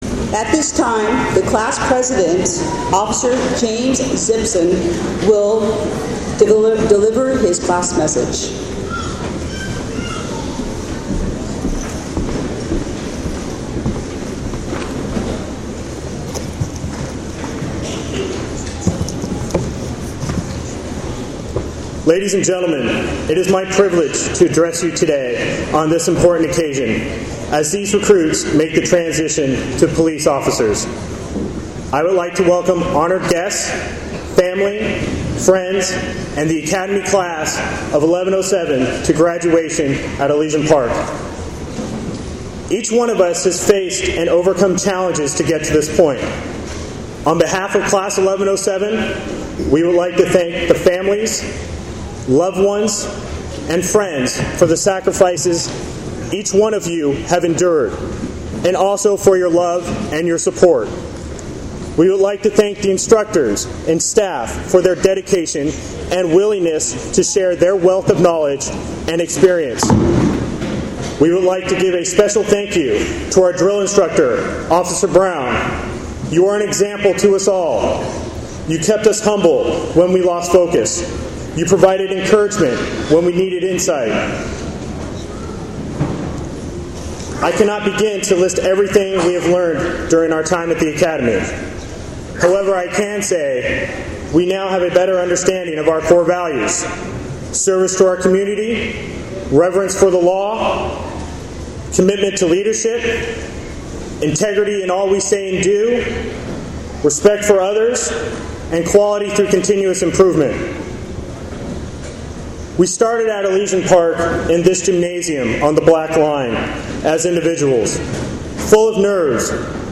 This morning, the Los Angeles Police Department welcomed its latest graduating recruits – Class 11-07.   Heralded in by the Los Angeles Police Department Concert Band, the newly minted officers held their heads high as they marched into the Academy Gymnasium, their uniforms and weapons gleaming in preparation for uniform inspection, which everyone passed.
Commissioner Robert Saltzman also congratulated the recruits on behalf of the Los Angeles Police Commission.